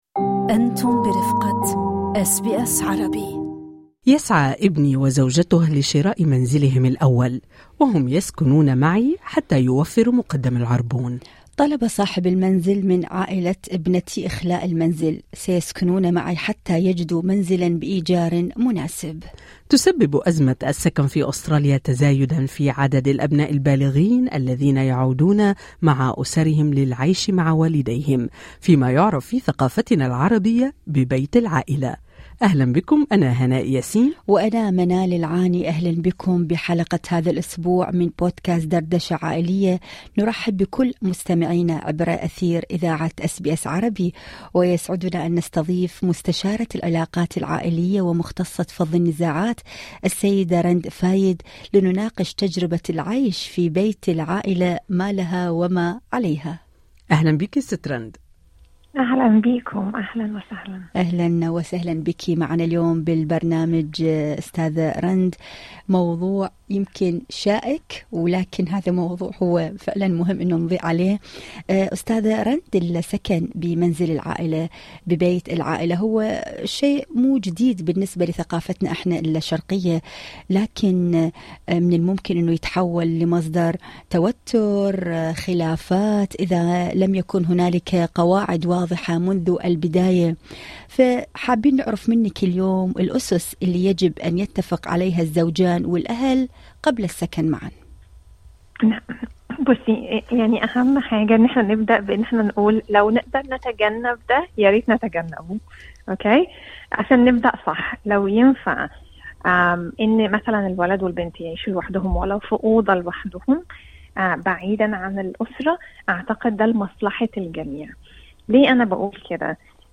العيش في بيت العيلة: هل هو خيار جيد ام وصفة للمشاكل؟ مستشارة عائلية تجيب